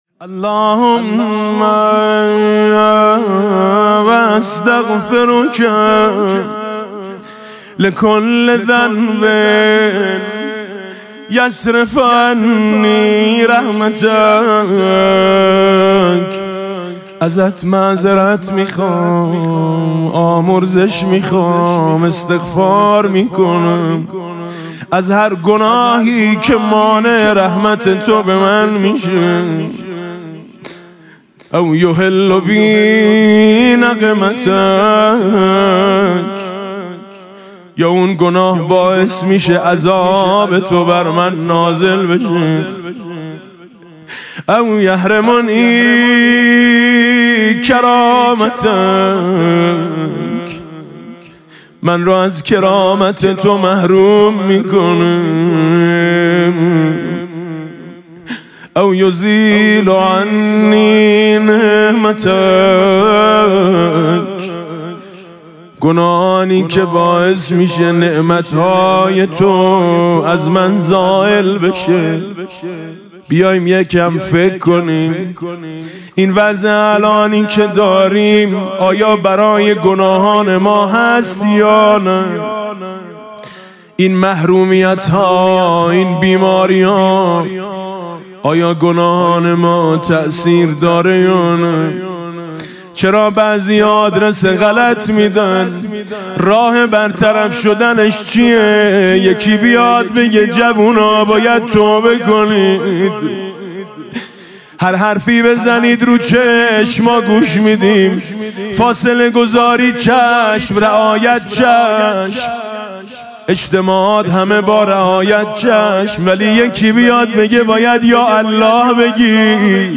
قرائت استغفار ۷۰ بندی حضرت امام علی علیه السلام با نوای دلنشین حاج میثم مطیعی